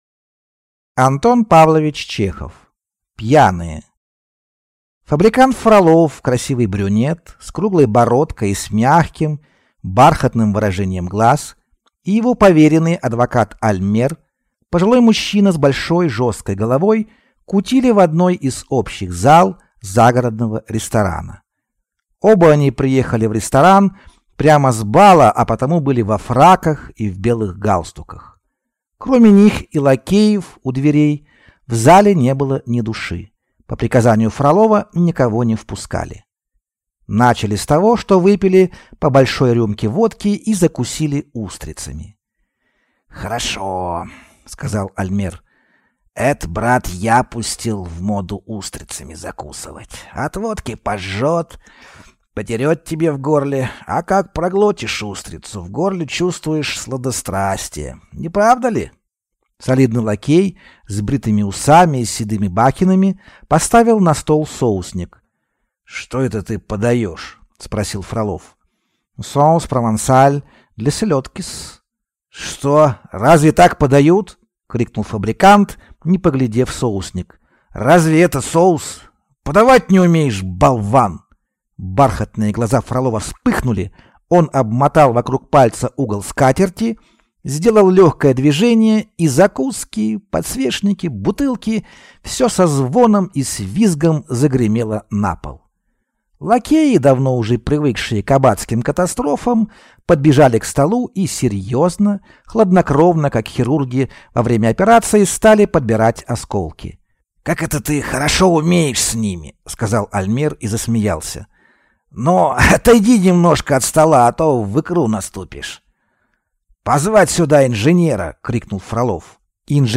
Аудиокнига Пьяные | Библиотека аудиокниг